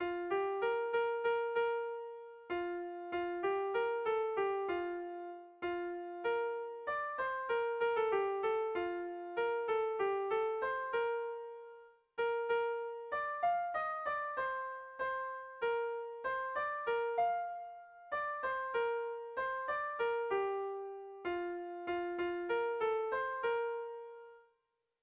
Bertso melodies - View details   To know more about this section
Irrizkoa
Zortziko txikia (hg) / Lau puntuko txikia (ip)